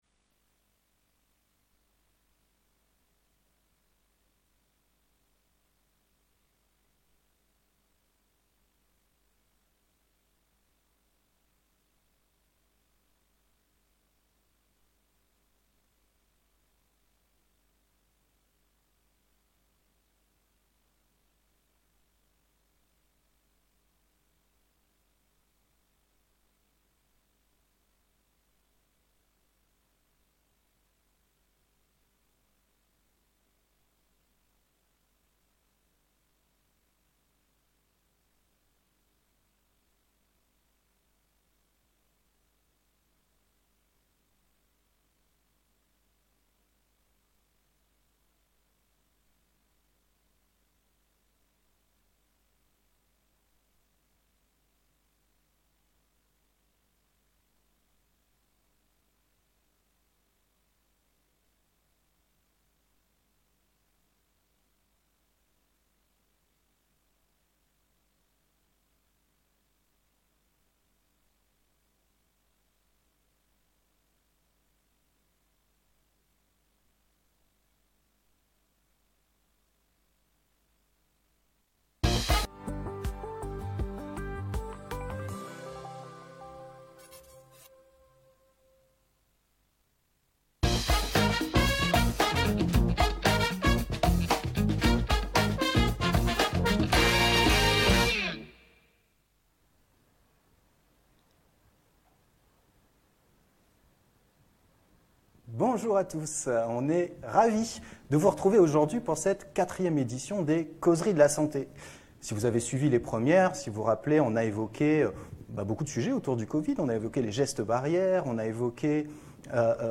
Ce spécialiste en pédopsychiatrie nous raconte quels sont les impacts de la crise du covid-19 sur nos jeunes